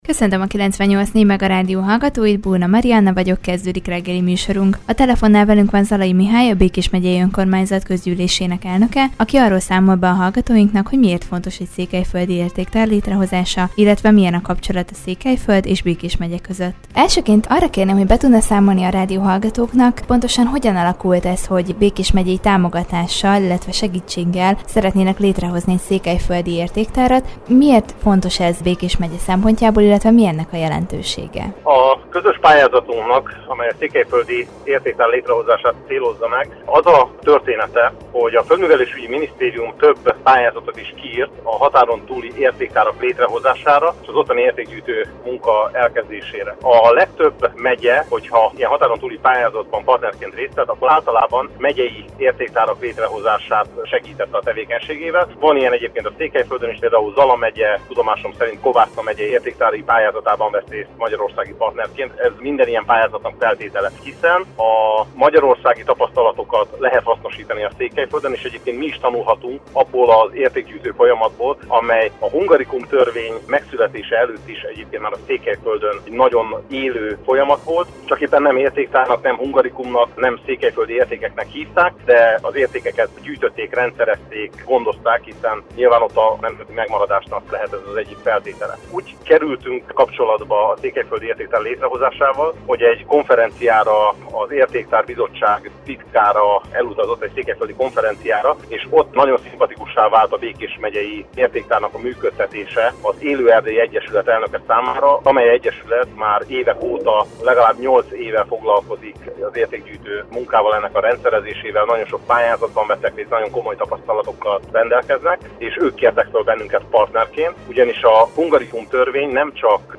Zalai Mihályt, a Békés Megyei Önkormányzat elnökét kérdeztük erről az együttműködésről.